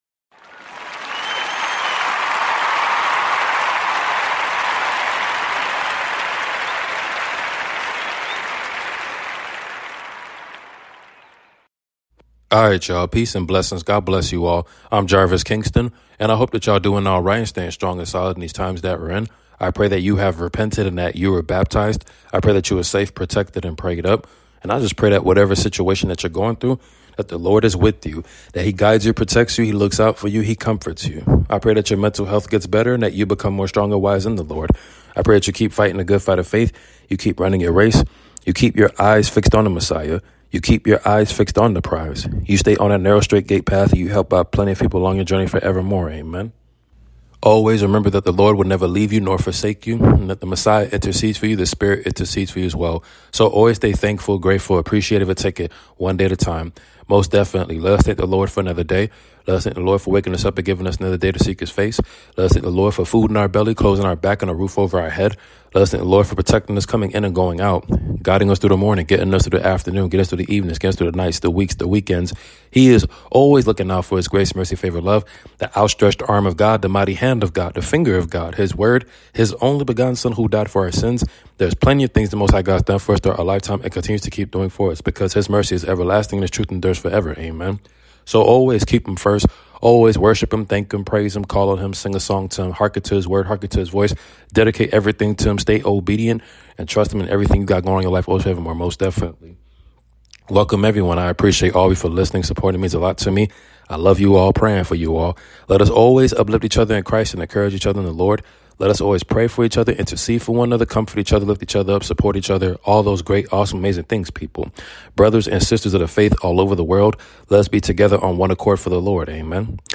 Your Nightly Prayer 🙏🏾 Genesis 1:28